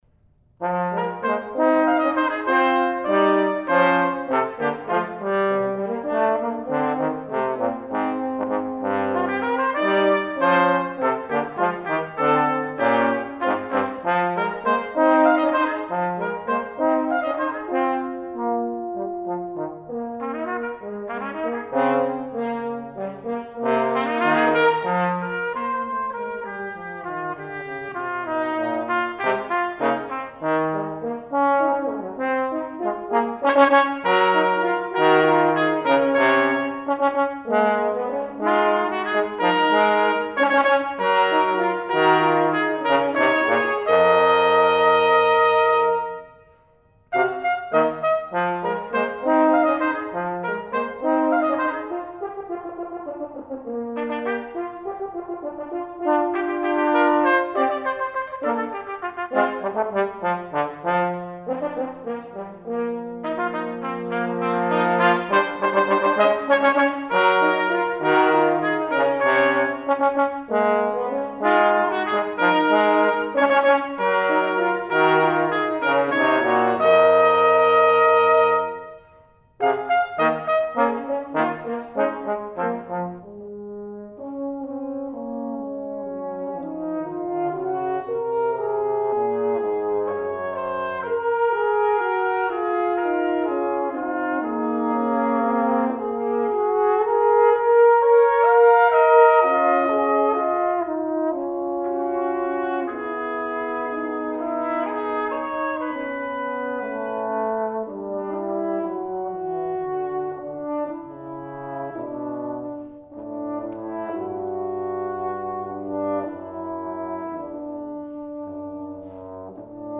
trumpet
trombone.